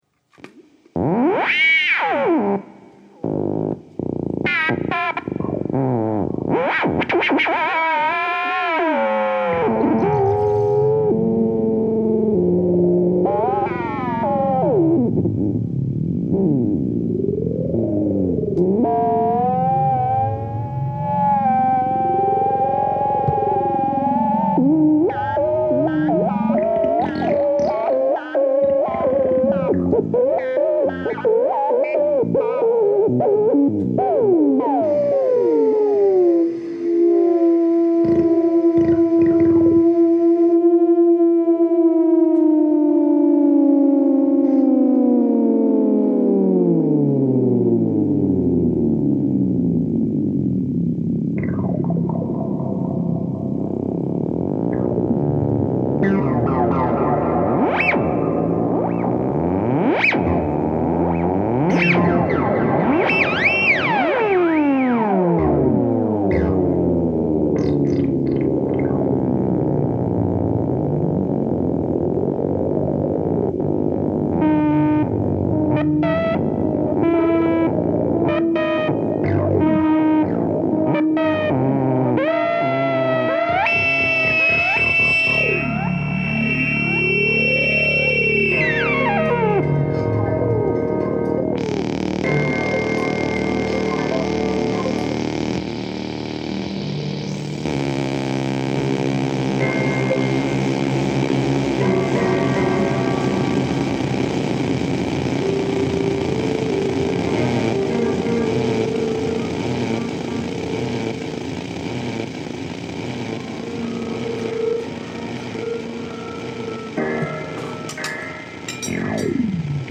Improv joint
vocals
drums
electronics